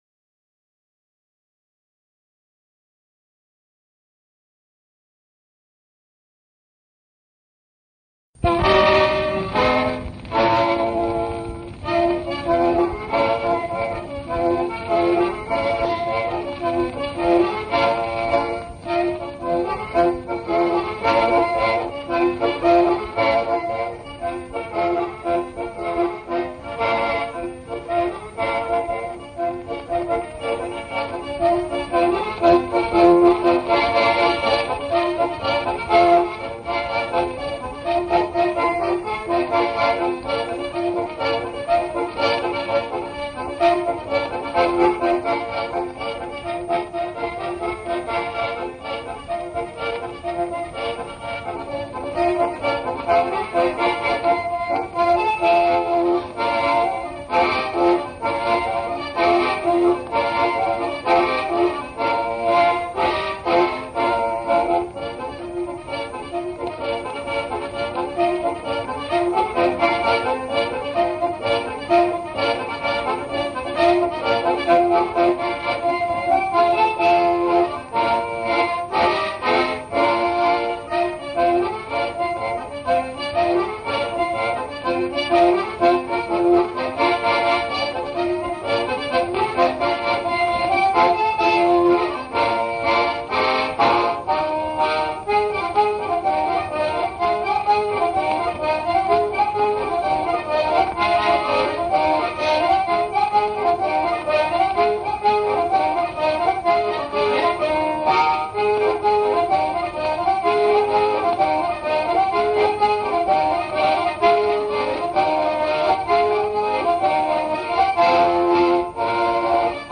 Su Pàssu Torràu  organetto diatonico